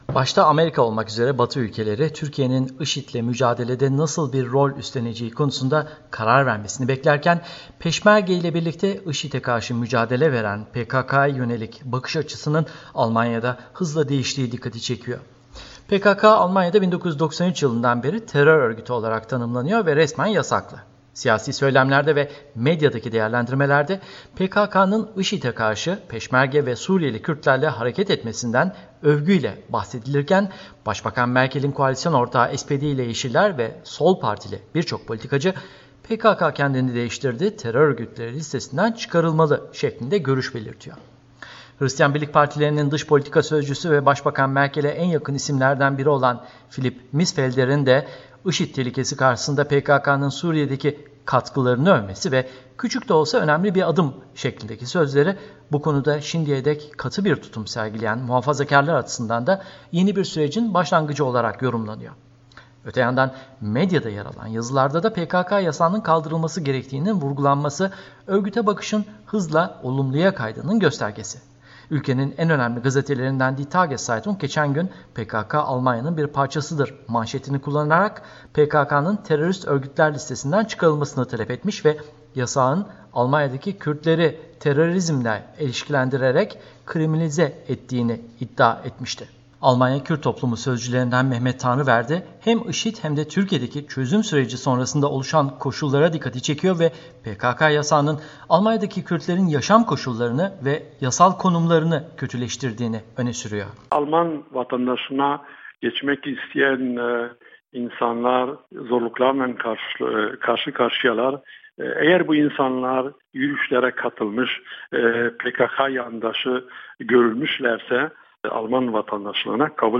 haberi